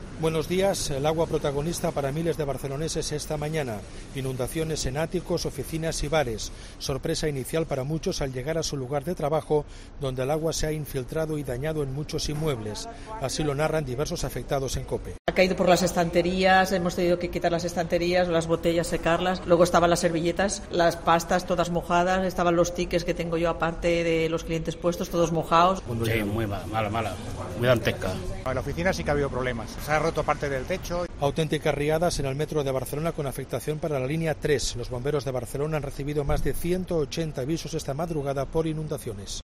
Cataluña bajo una tromba de agua. Crónica